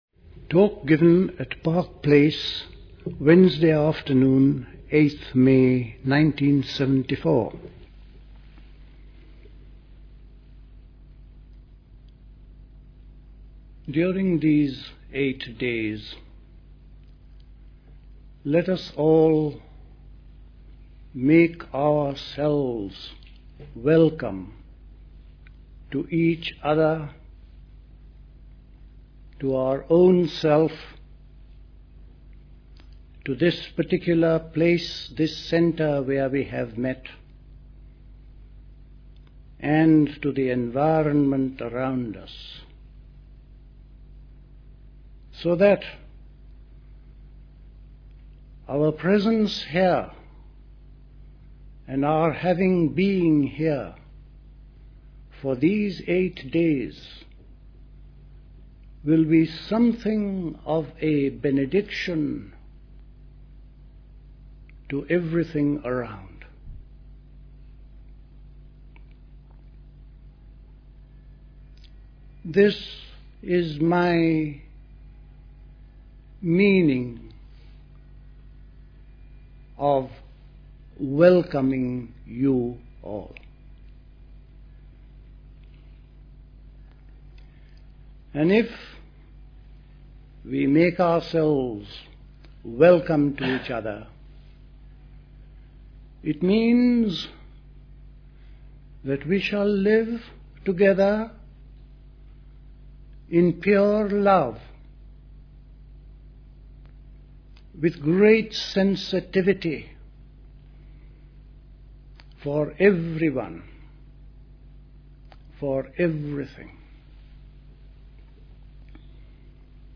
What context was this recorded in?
Recorded at the 1974 Park Place Summer School. This was the opening talk.